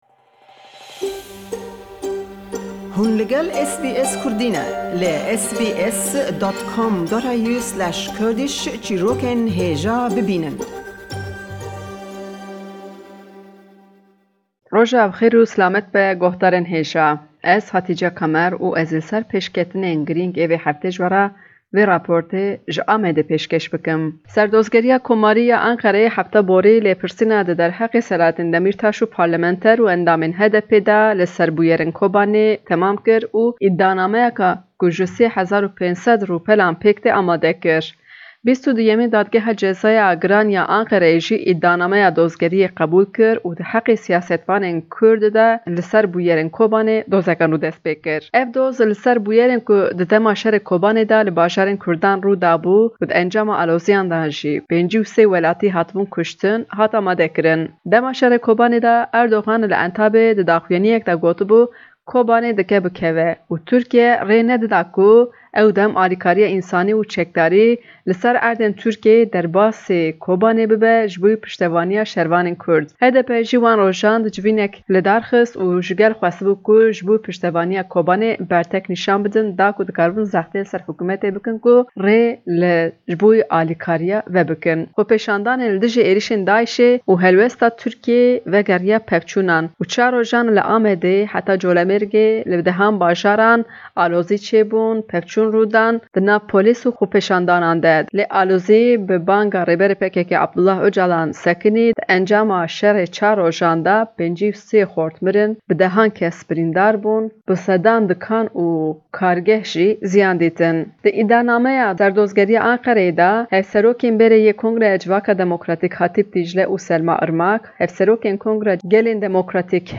Raporta vê heftê ji Amedê: li ser doza nû ya li ser bûyerên Kobanê ku di heqê Selahattîn Demirtaş û 108 siyasetvanên kurd hatiye vekirin.